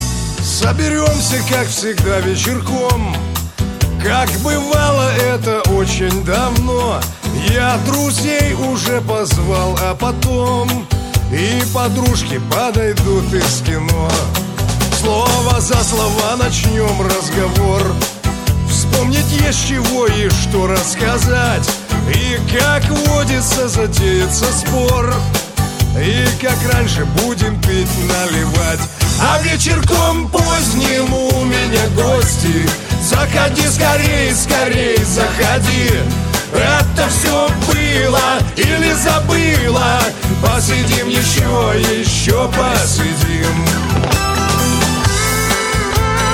Категория: Нарезки шансона